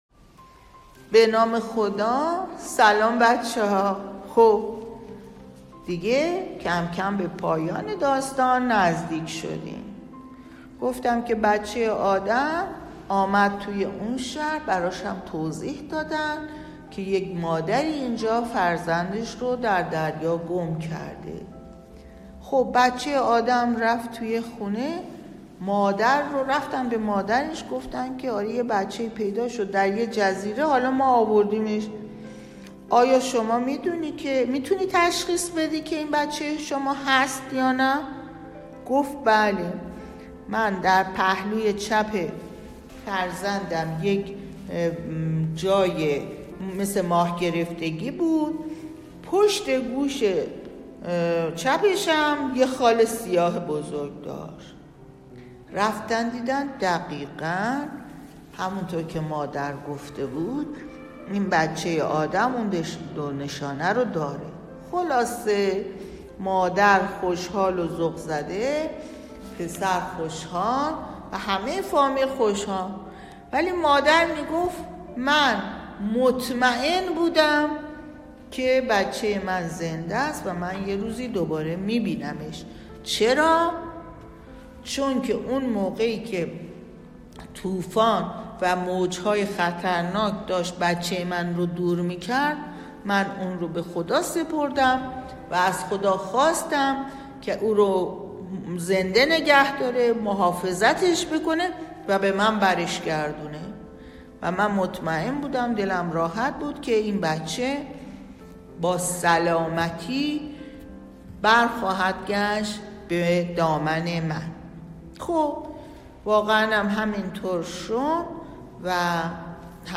باهم بشنویم ، داستان دنباله دار. قسمت یازدهم